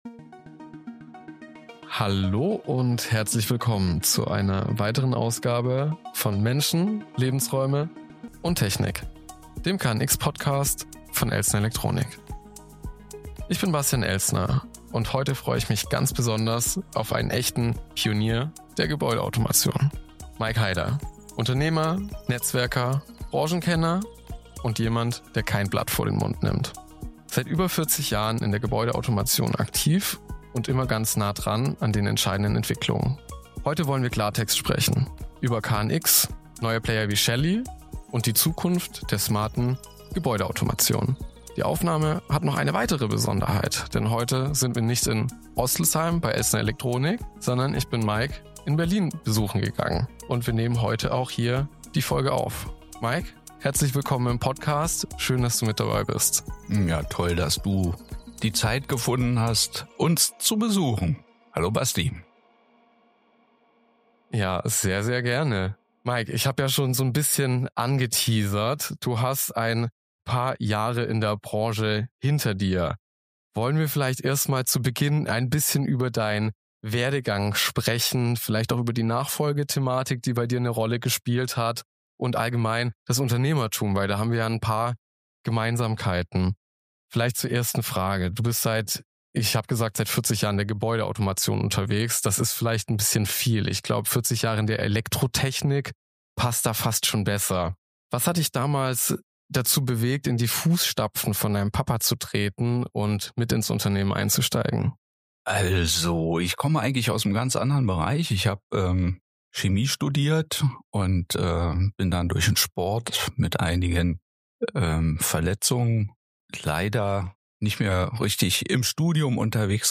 Ein ehrliches Gespräch über Nachfolge, Datenräume und die Zukunft der smarten Gebäudetechnik.